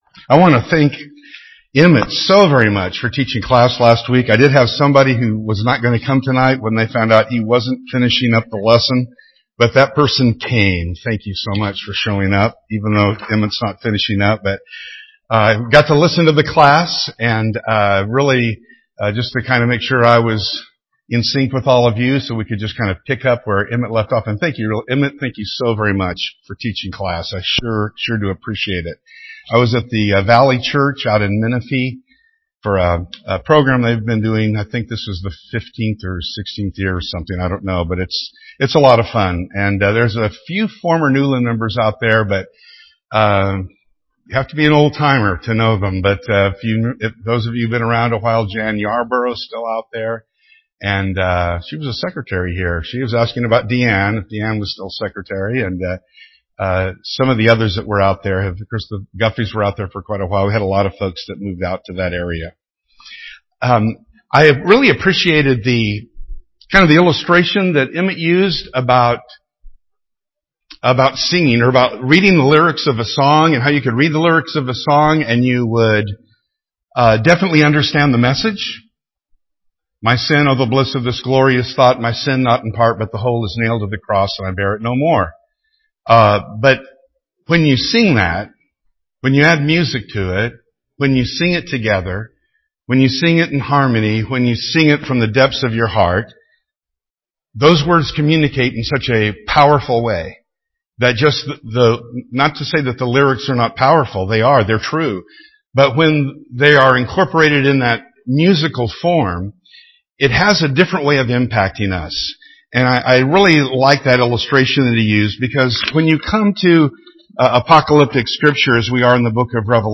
This is the sixth part of our Wednesday night class on Revelation.